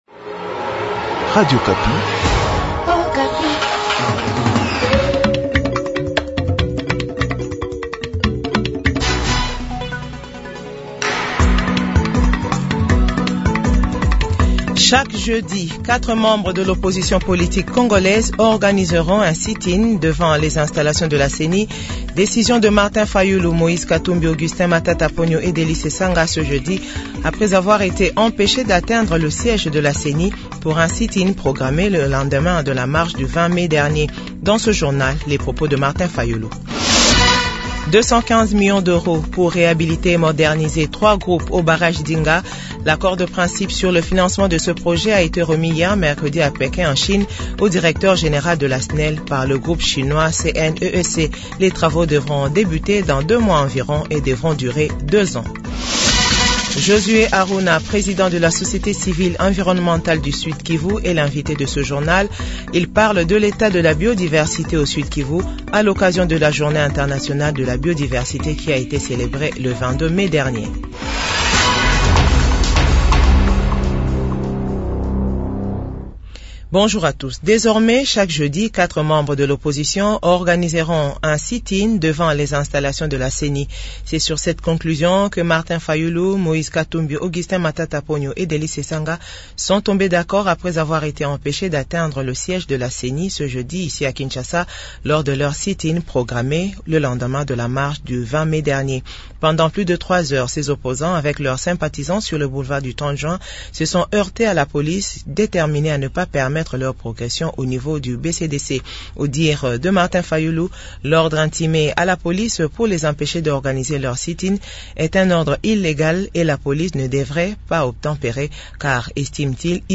Journal Francais De 15h00